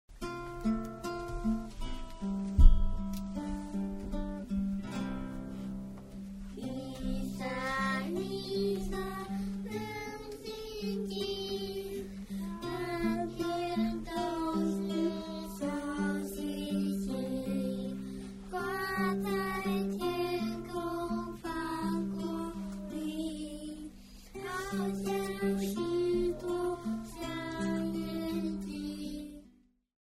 一張天真、單純、真實的紀錄片配樂專輯